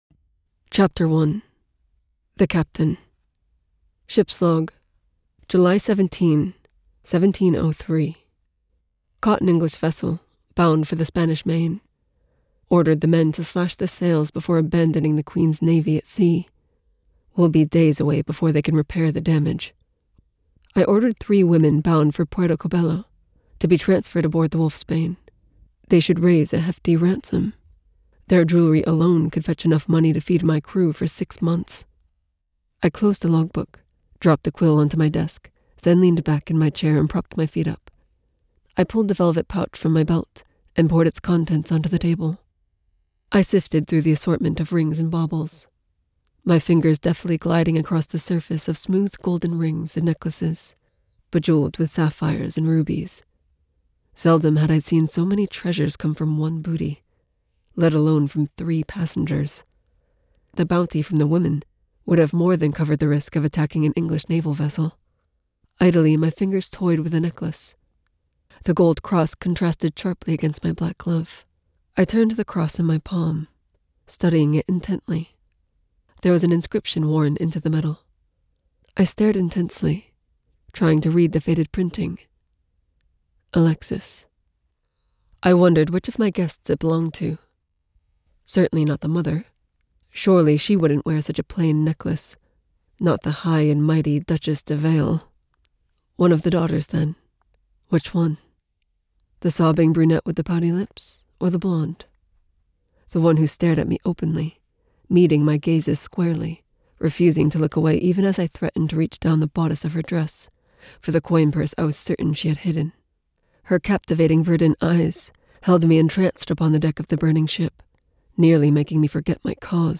Taming the Wolff by Del Robertson [Audiobook]